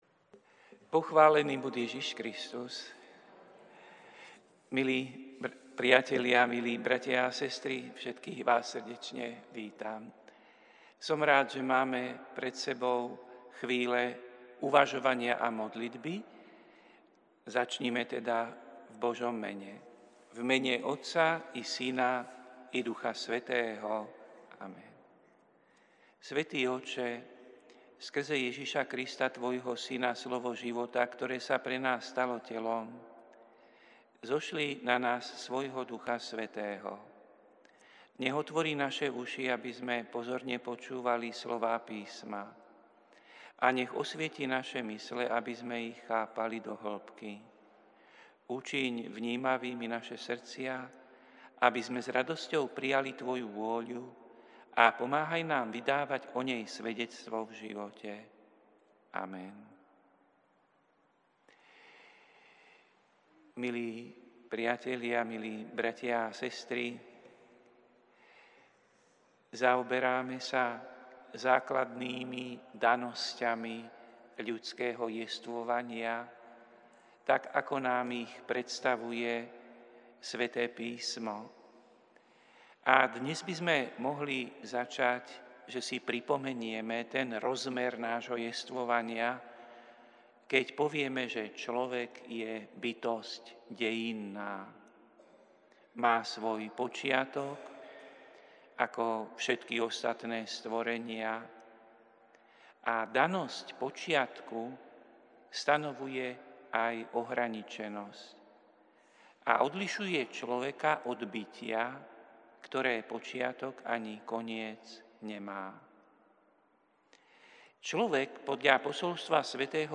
Prinášame plný text a audio záznam z Lectio divina, ktoré odznelo v Katedrále sv. Martina 1. mája 2024.